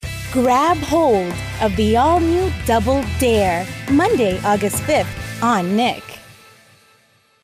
Female
Bright, Bubbly, Character, Children, Confident, Engaging, Friendly, Reassuring, Soft, Warm, Versatile, Young
My voice is naturally warm, comforting, bubbly, joyful , and conversational.
Microphone: Sennheiser 416 , Rode NT1-A